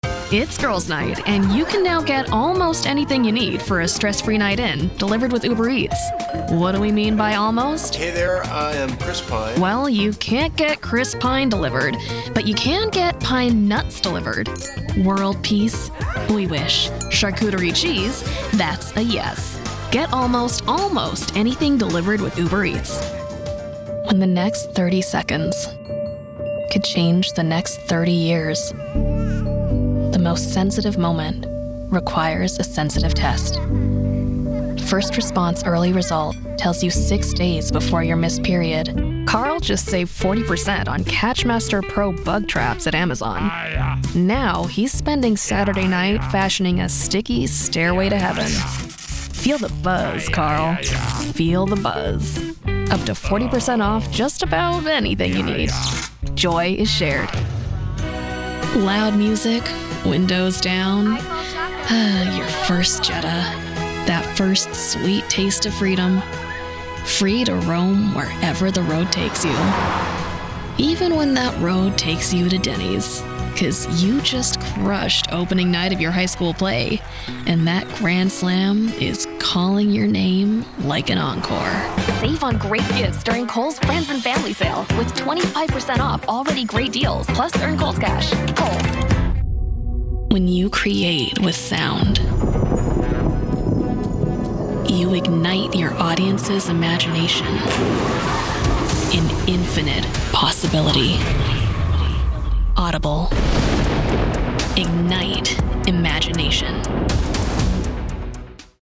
Englisch (Amerikanisch)
Kommerziell, Natürlich, Verspielt, Vielseitig, Freundlich
Kommerziell